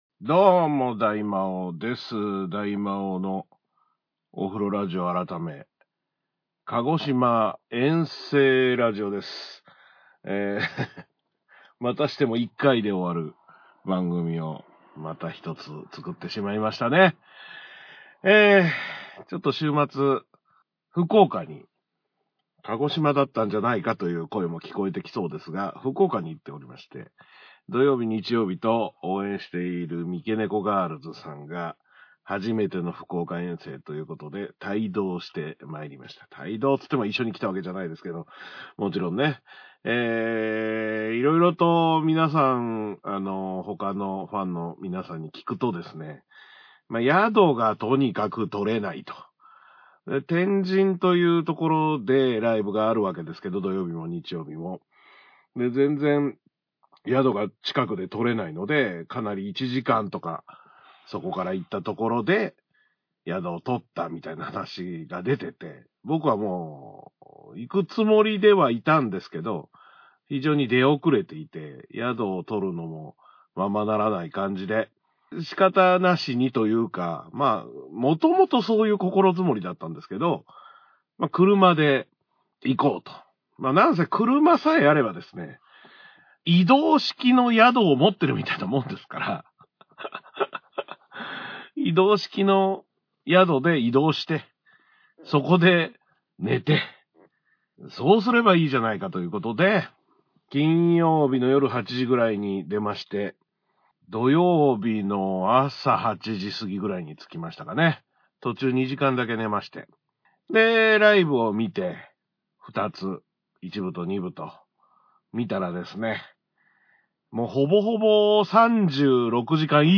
今回は鹿児島遠征中の移動宿泊施設からお送りします
今回は遠征お車ラジオ